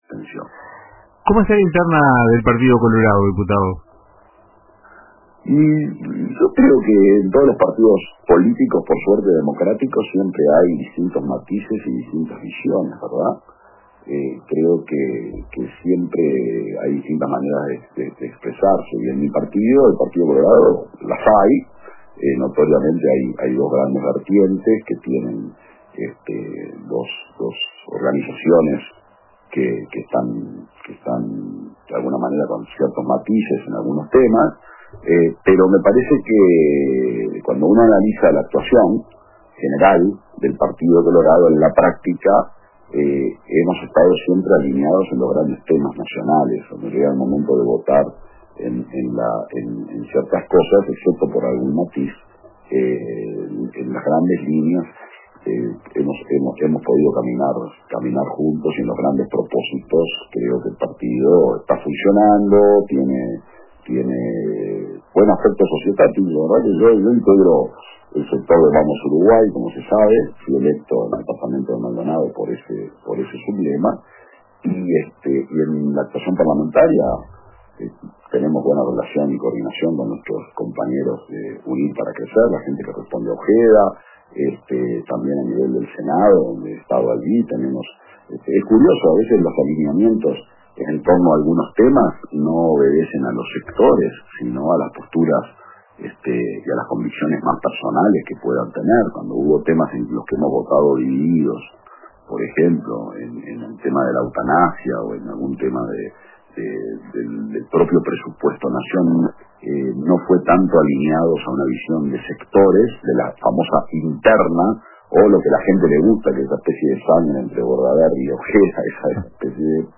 Durante su participación en Radio con Todos de RBC, Gurméndez cuestionó la falta de un plan de seguridad del actual gobierno, la ausencia de refuerzos policiales prometidos y el retraso en políticas carcelarias.